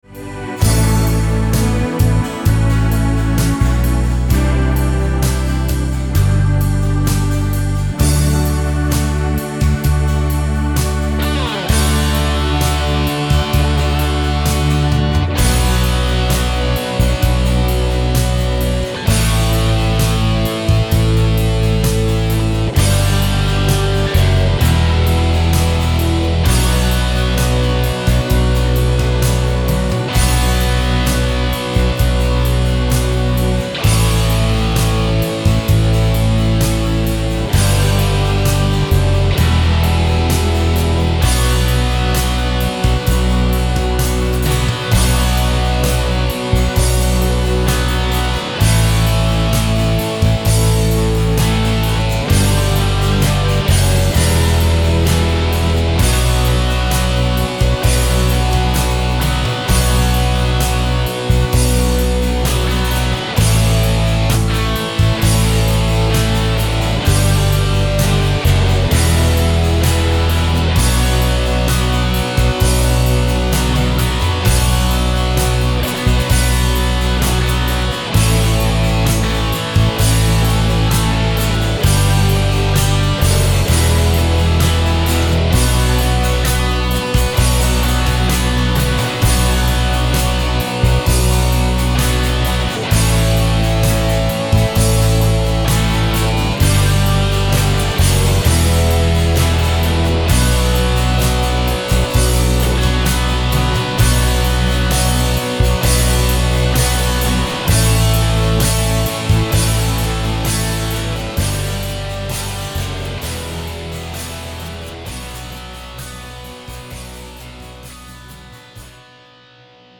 SOLO2 (with Acust&Dist)
130 bpm